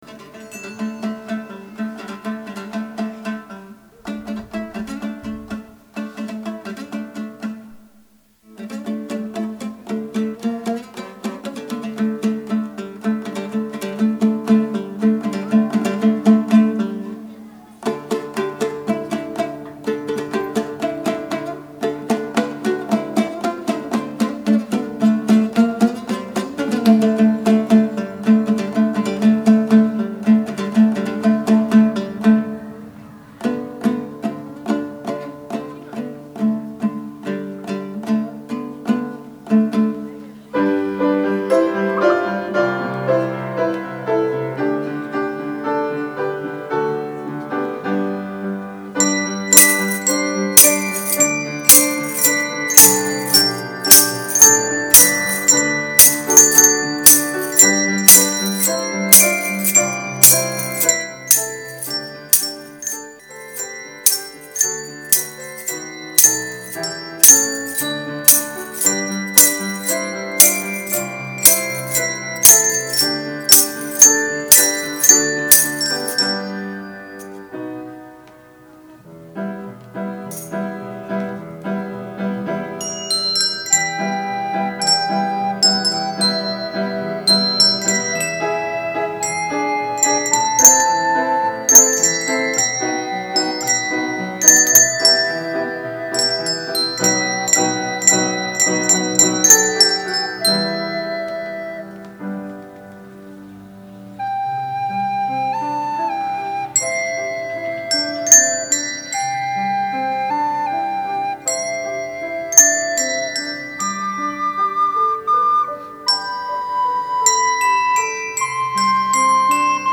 on 2014-07-08 - Фестиваль христианской музыки и песни
подростковый_ансамбль__Костанай_
61511-Поппури_-_подростковый_ансамбль__Костанай_.mp3